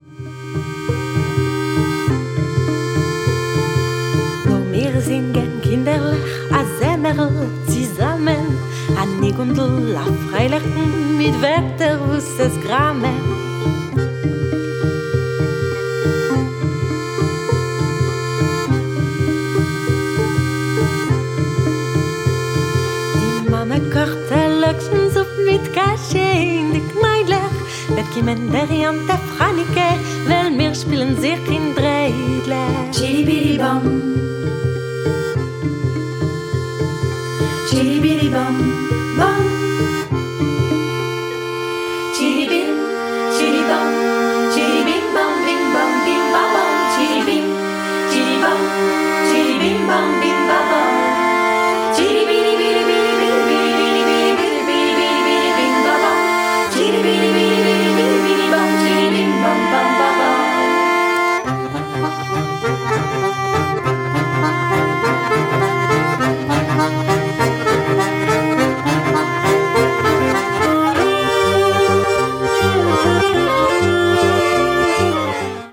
Clarinete – Voz
Acordeón – Voz
Violín – Coros
Violonchelo – Coros